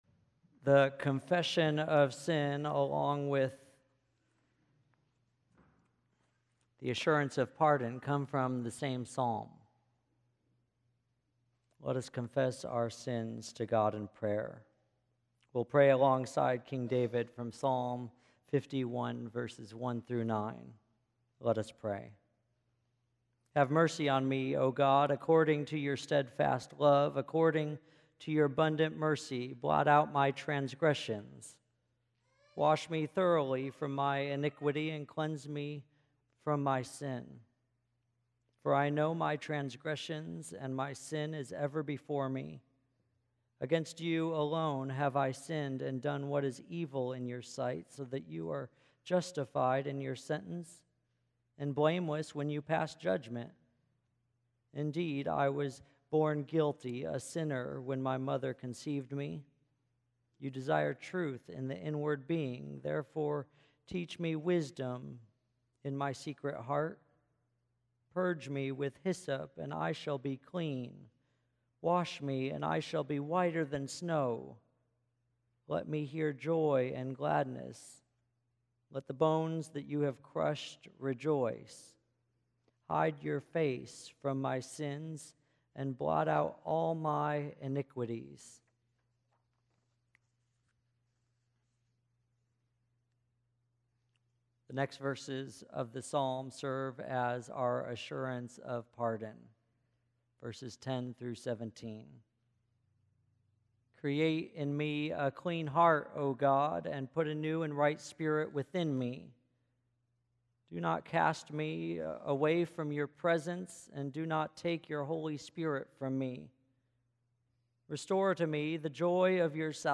Ash Wednesday 2017 HOMILY